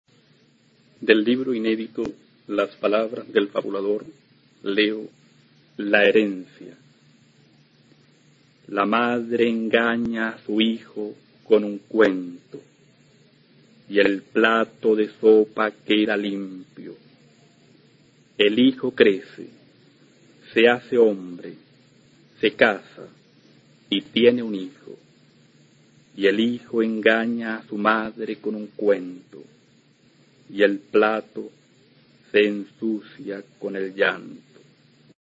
recitando su poema La herencia, del libro "Las palabras del fabulador" (1968).
Poema